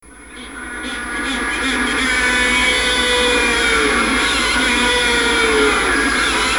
Spheniscus magellanicus - Pingüino de magallanes
Intercalan esta acción con ásperos sonidos que recuerdan a rebuznos.
pinguinomagallanes.wav